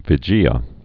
(vĭ-jēə, -hē-)